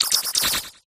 milcery_ambient.ogg